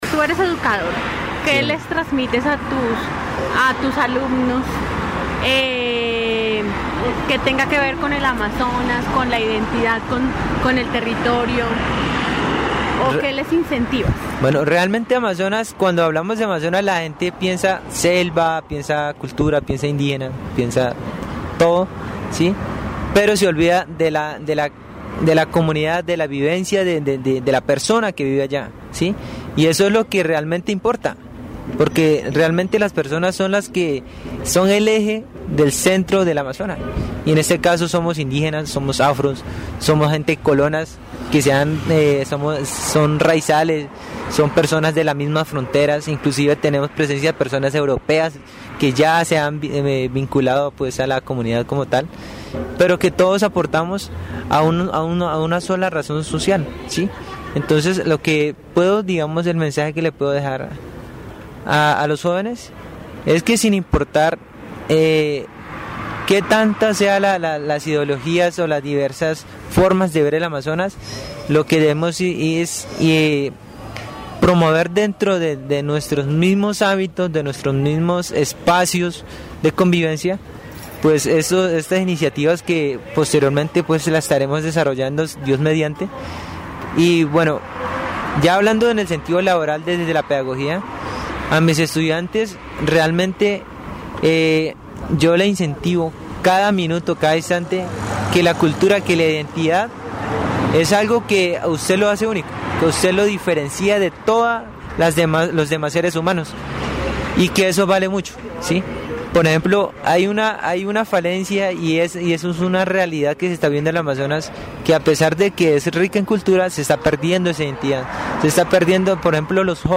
En esta entrevista, se aborda la importancia de la identidad cultural en el Amazonas y cómo la educación puede ser un motor para su preservación.
Amazonas (Región, Colombia) -- Grabaciones sonoras , Programas de radio , Identidad cultural en el Amazonas , Pérdida de identidad en la juventud amazónica , Promoción de la identidad en la educación